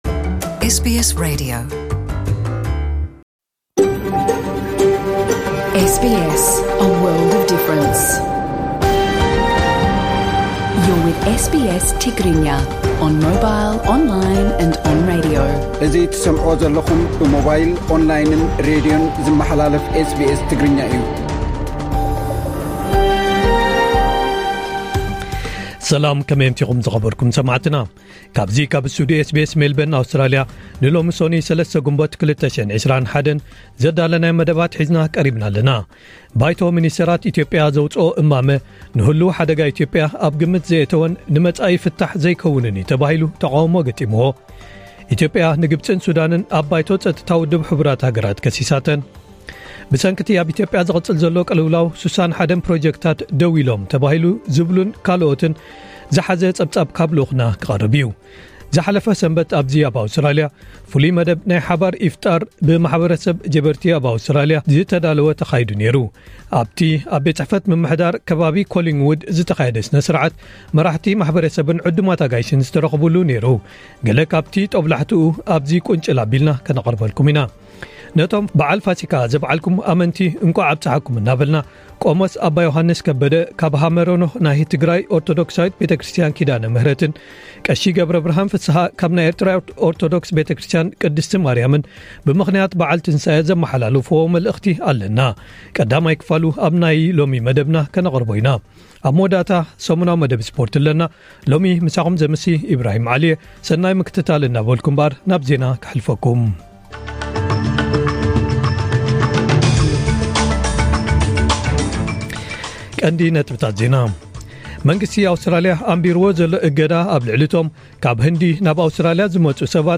ዕለታዊ ዜና ኤስቢኤስ ትግርኛ